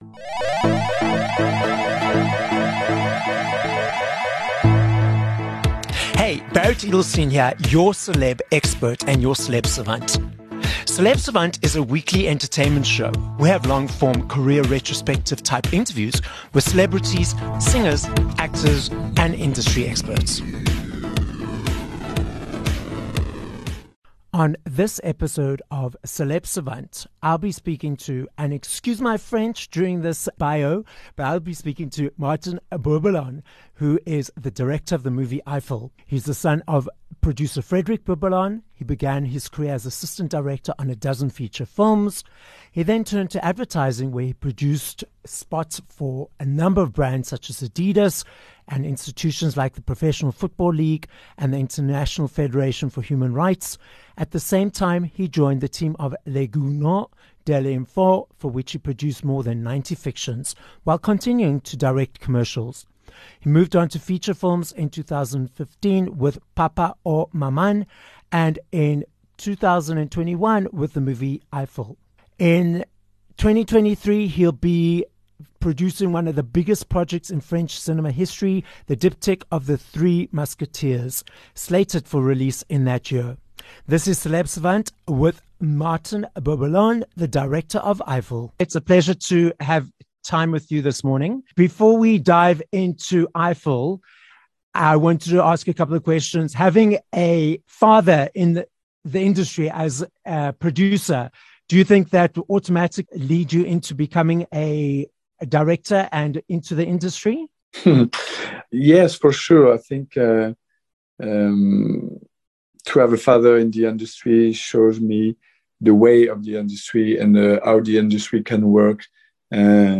20 Aug Interview with Martin Bourboulon (Director of Eiffel)